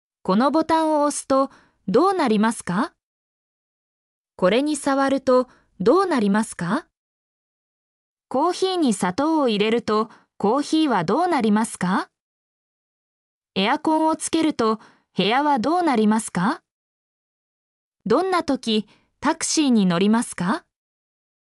mp3-output-ttsfreedotcom-73_Sbt7E0Zl.mp3